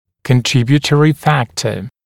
[kən’trɪbjuːtərɪ ‘fæktə][кэн’трибйутэри ‘фэктэ]способствующий фактор